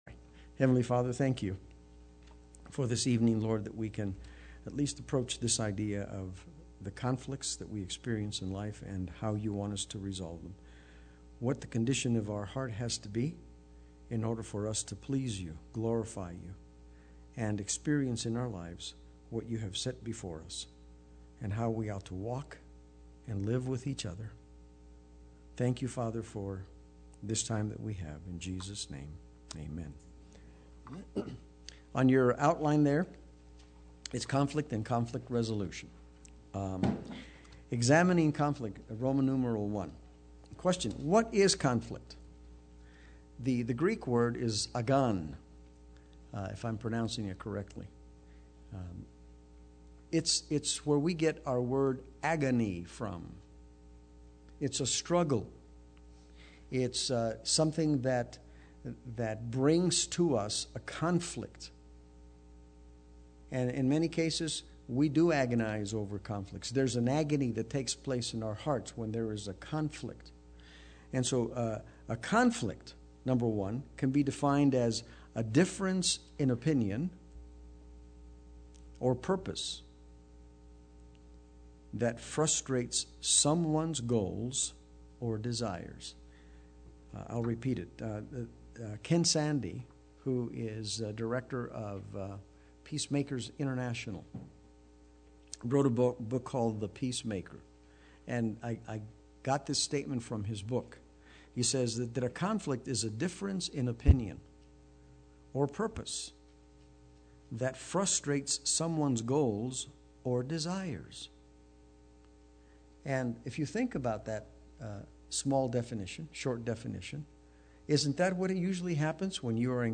Play Sermon Get HCF Teaching Automatically.